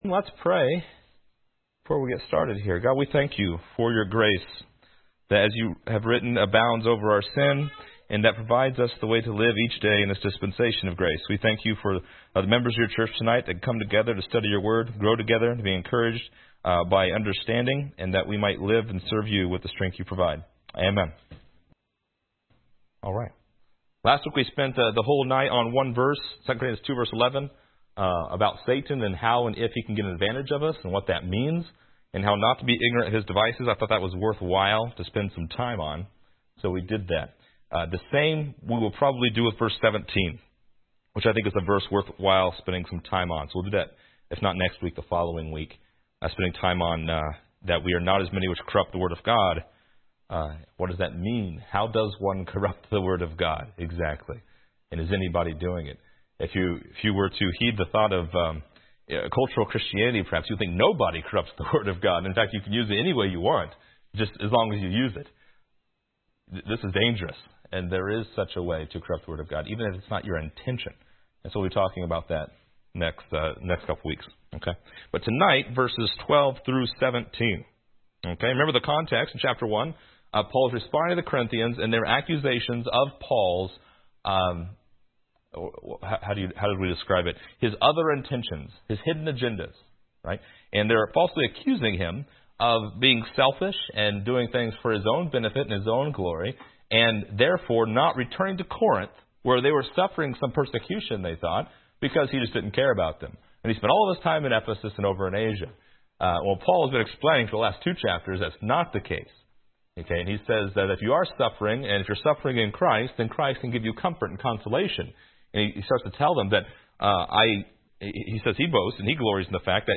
Download MP3 | Download Outline Description: This lesson is part 8 in a verse by verse study through 2 Corinthians titled: Troas and Triumph in Christ .